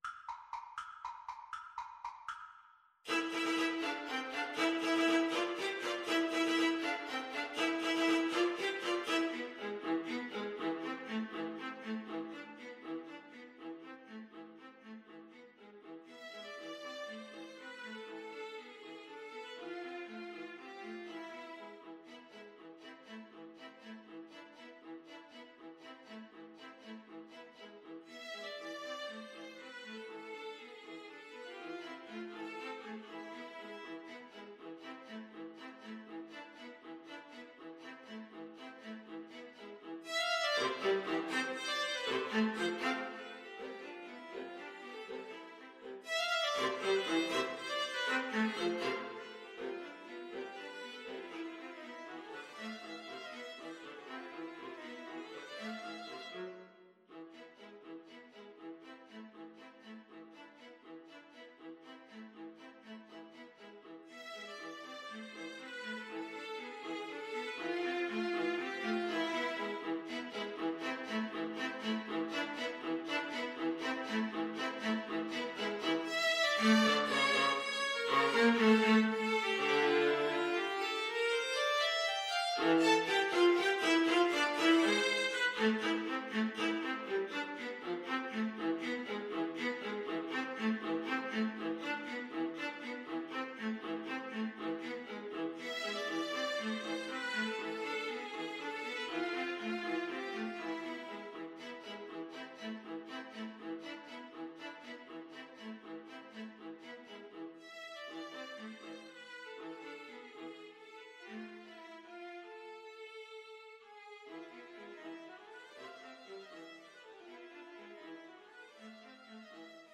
Allegro vivo (.=80) (View more music marked Allegro)
Viola Trio  (View more Advanced Viola Trio Music)